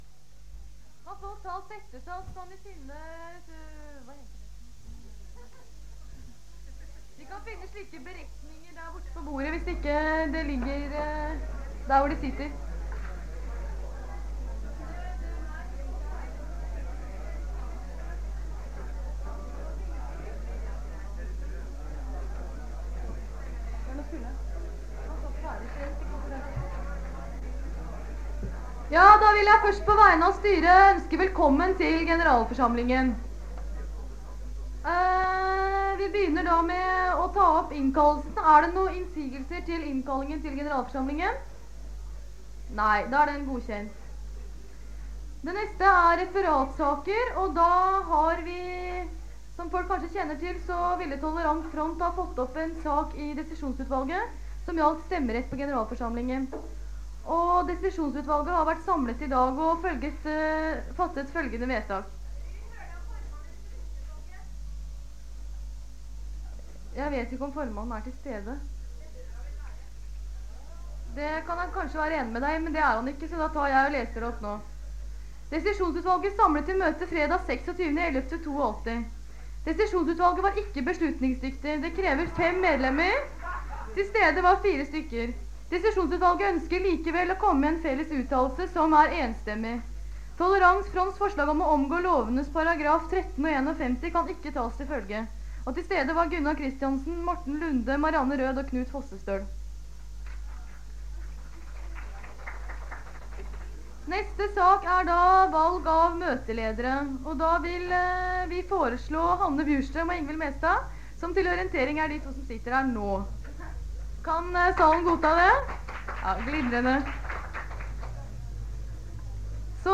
Det Norske Studentersamfund, Generalforsamling, 26.11.1982 (fil 1-2:4)